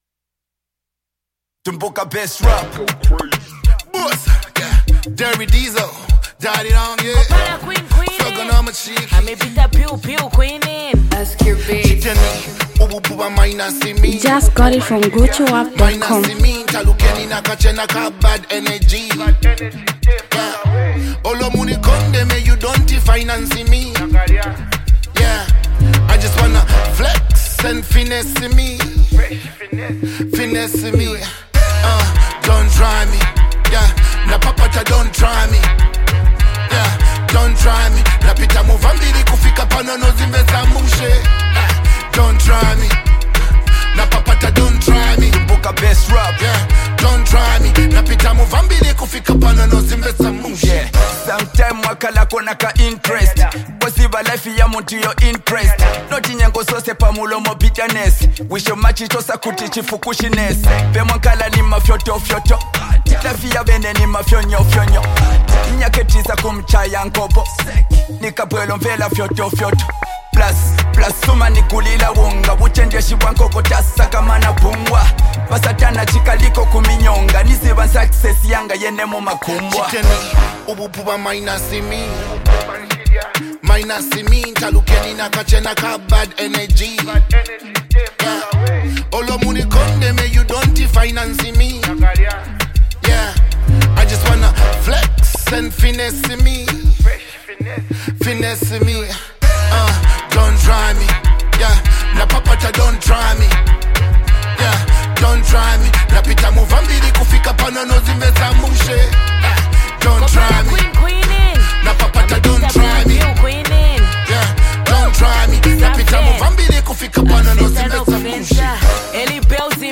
Zambian Mp3 Music
powerful lyrical jam